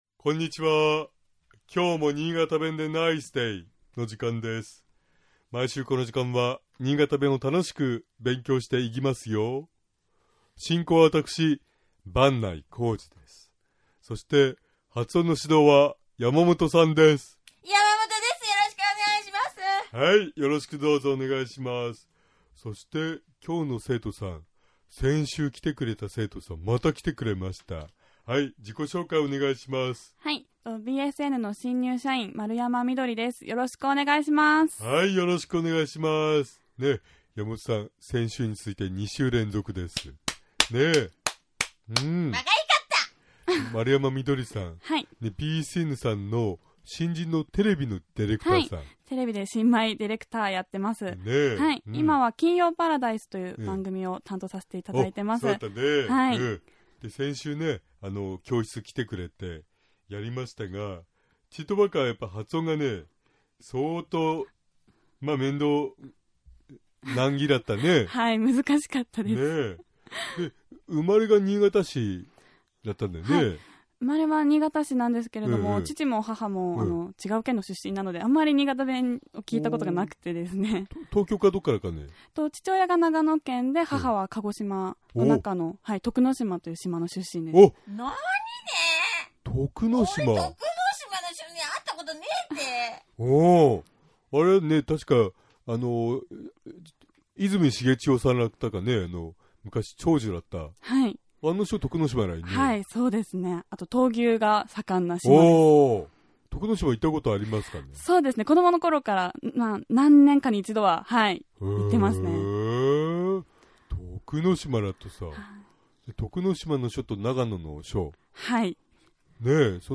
今回のポイントは「くゎ」の発音です。
従って、「菓子」の事を「くゎし」、「会社」は「くゎいしゃ」、「火事」は「くゎじ」、 「買い物」は「くゎいもん」、「観音様」は「くゎんのんさま」と発音されます。
（スキット） （妻）うんめはこのくゎし。